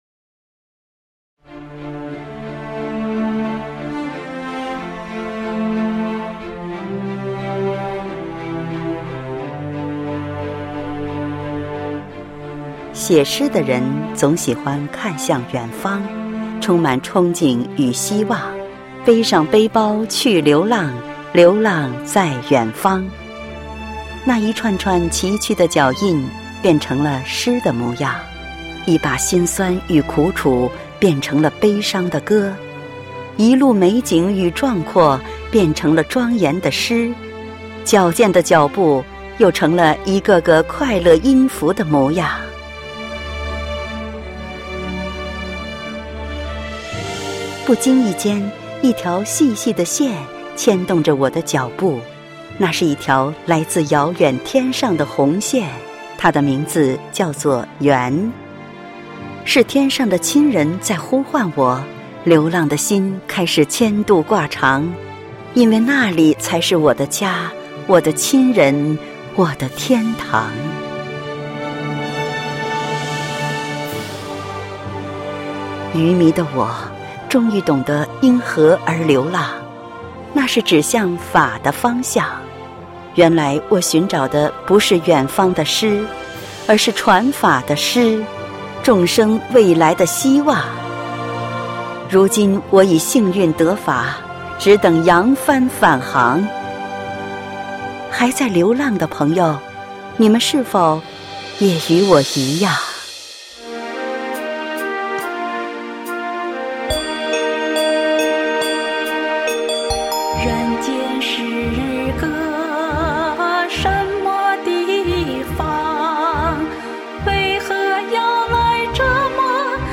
配樂散文朗誦（音頻）：詩的故鄉在遠方